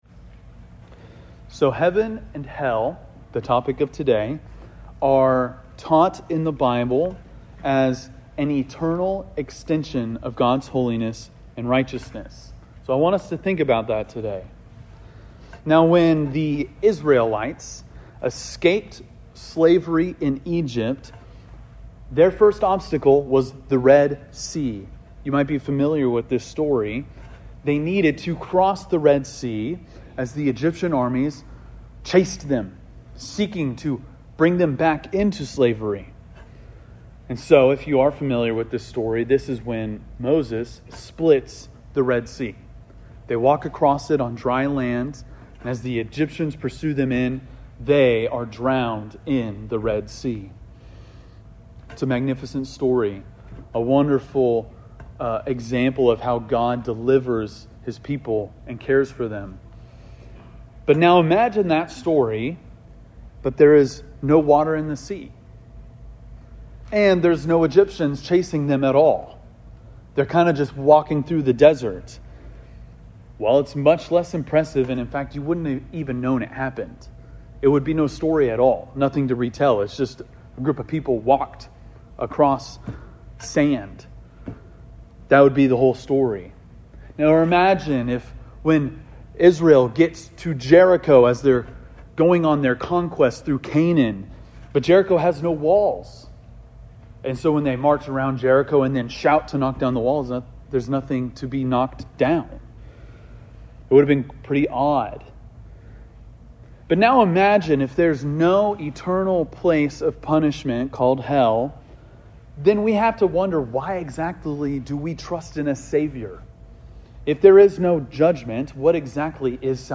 preaches on the reality and fairness of heaven and hell.&nbsp